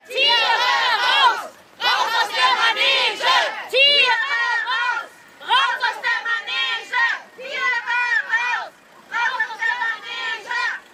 Abschließend noch einige der „Kampfrufe“ als Audio-Dokumente.
Demonstration gegen Zirkustiere (Audio 3/6)